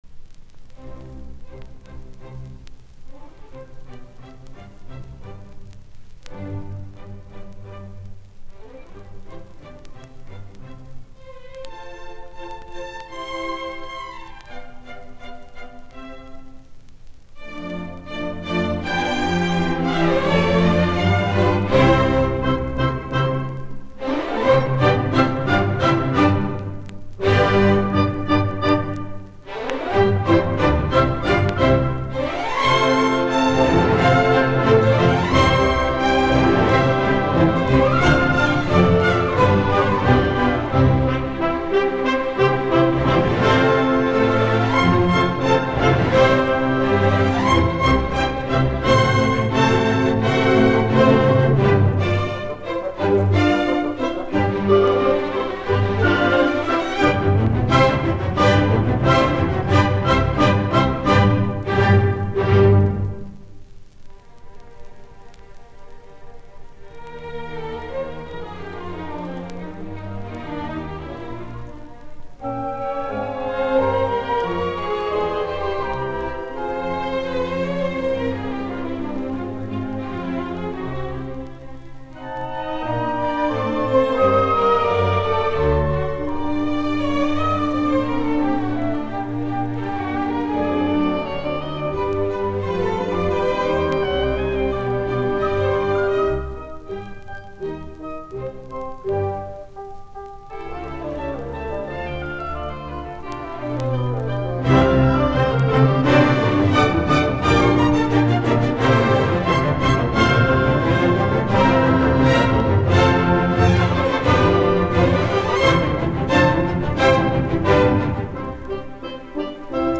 Concerto No. 1 in C Major for piano and Orchestra, Op. 15